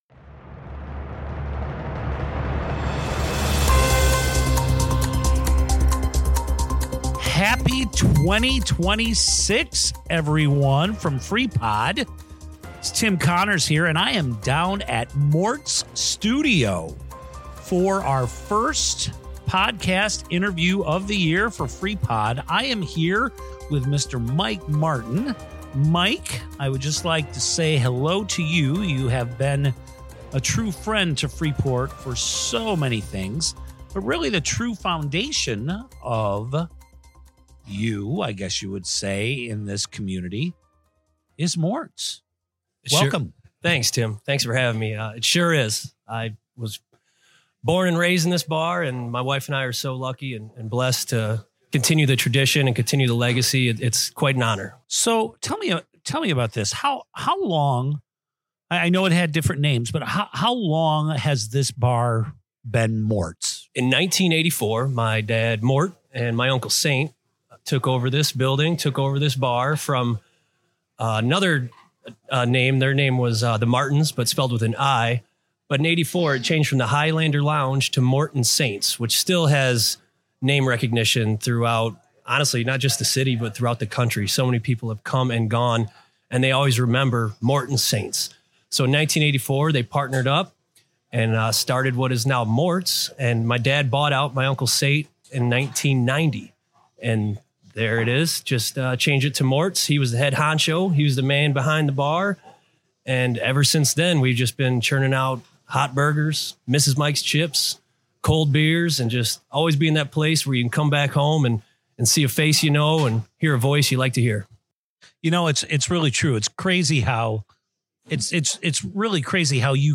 Freepod Interview